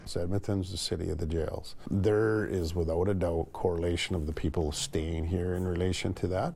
Edmonton’s police chief Dale Mcfee has stated that the result is inmates remaining in the City following their release :
dale-mcfee-edmonton-city-of-jails.mp3